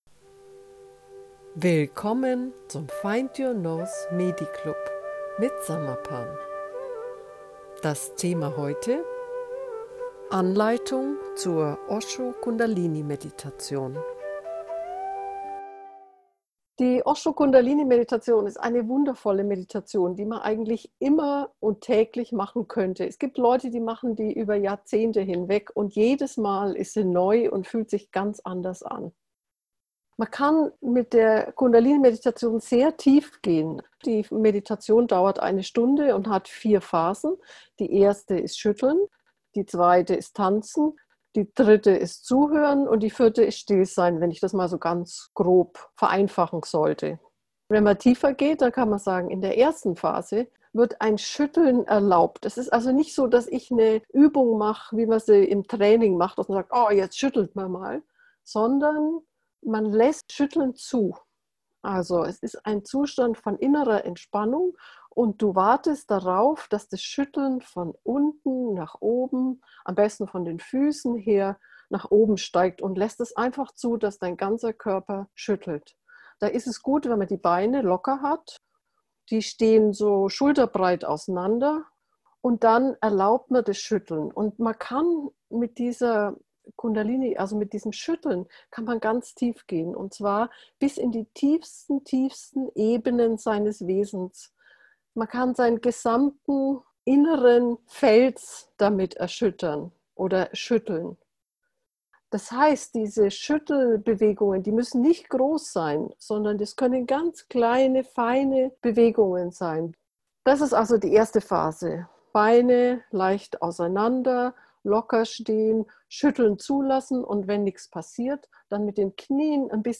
anleitung-osho-kundalini-meditation